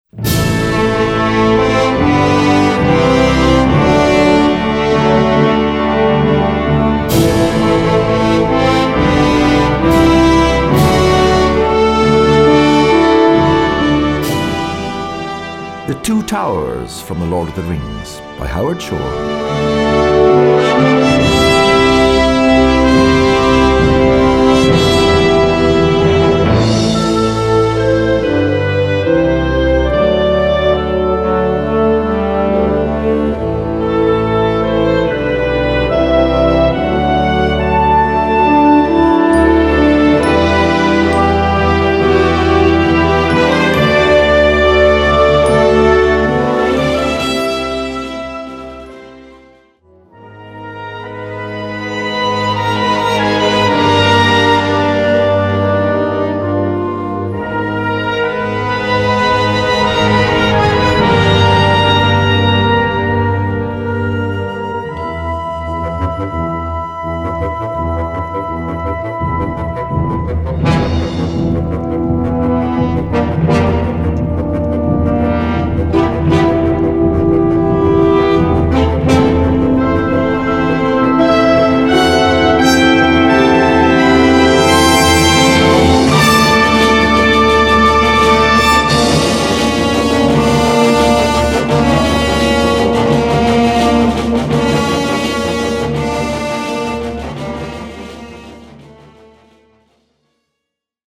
Gattung: Filmmusik
Besetzung: Blasorchester